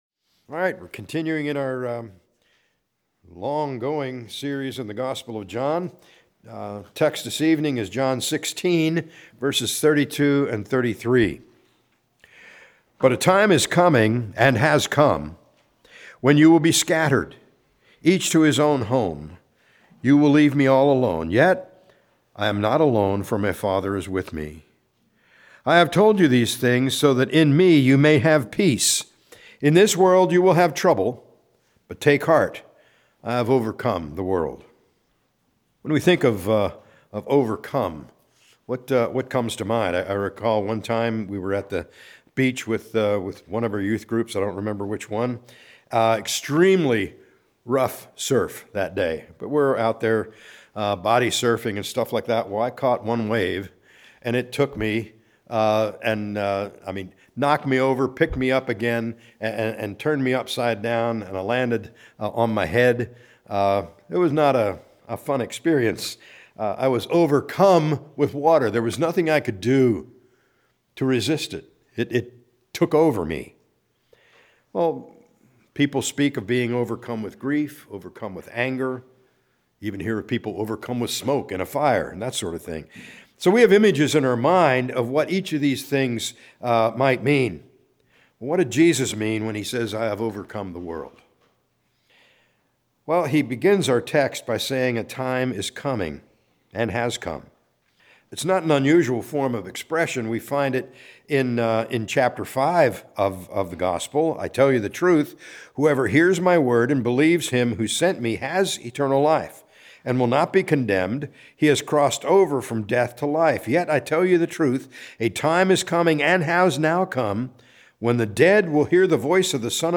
A message from the series "John."